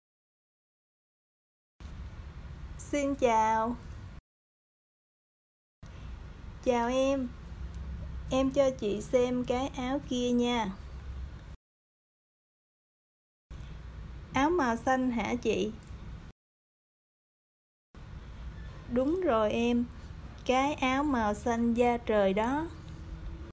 dialog-achats-partie-1.mp3